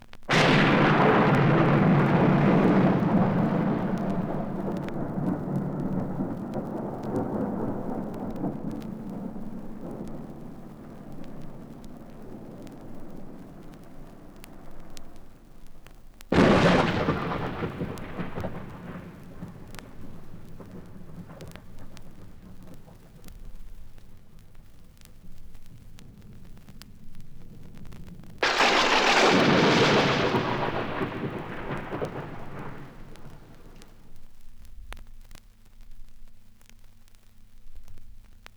• three thunderclaps.wav
three_thunderclaps_yio.wav